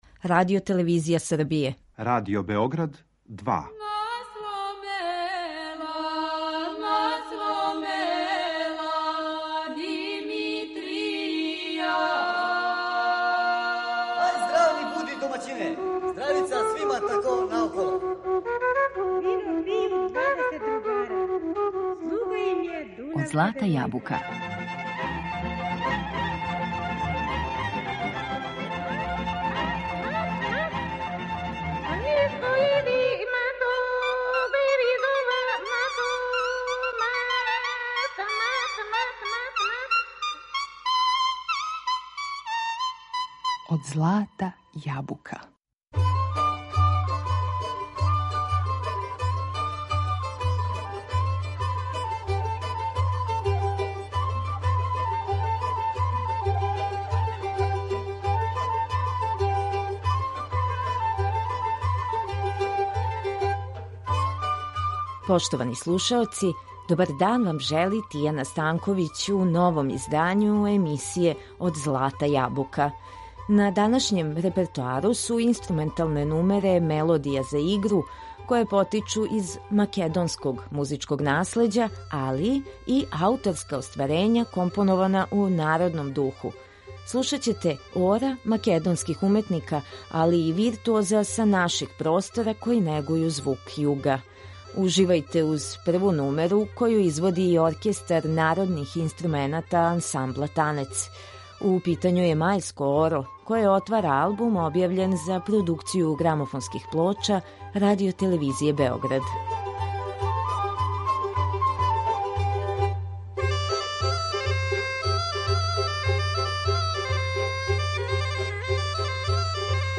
Ове мелодије за игру плене лепотом мелодије и комплексним и разноврсним непарним ритмичким облицима.